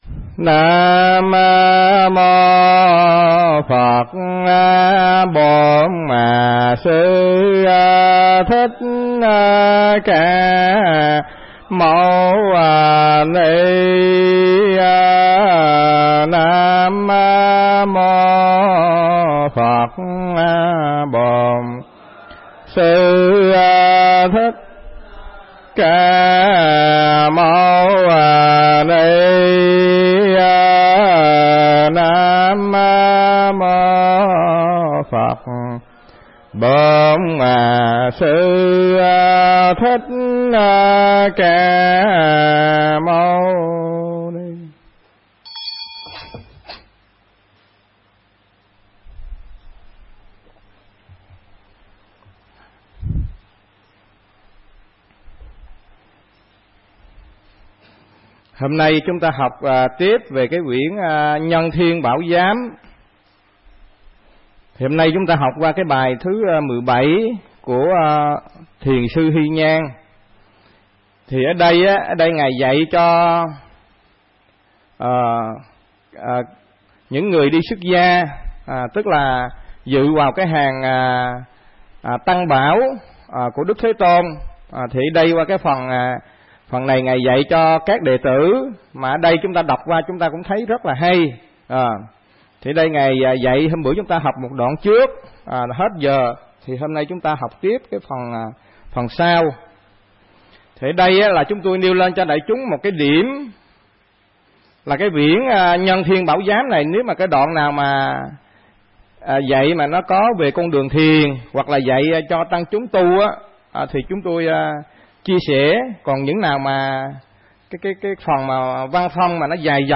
Cập nhập buổi giảng phần cuối.